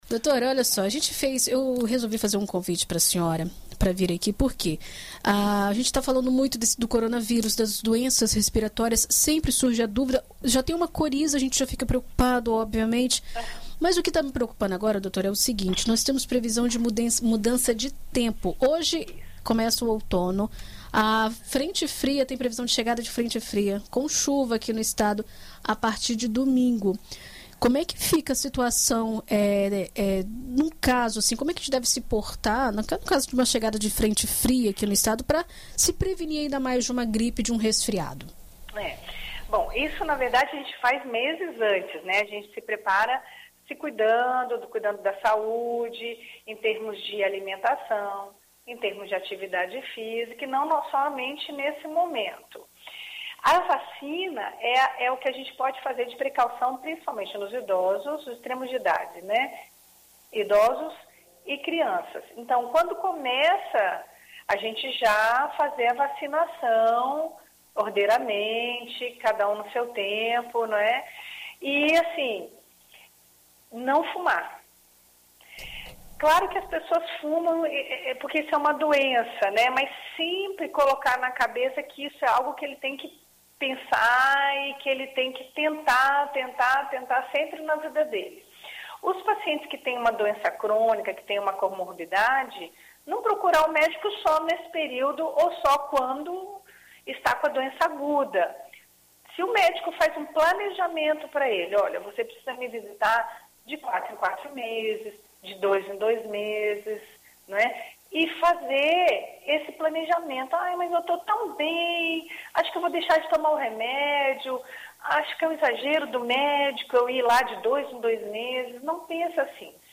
Em entrevista à BandNews FM